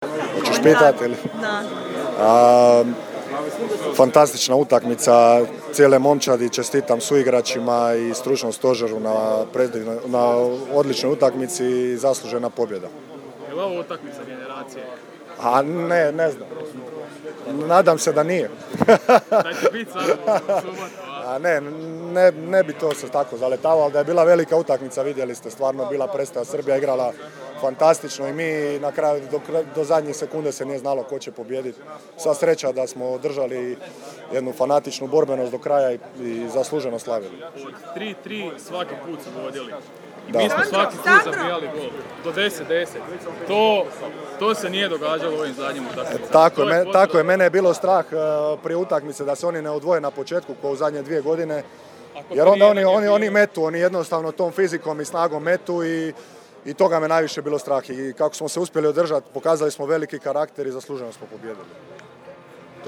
IZJAVE POBJEDNIKA: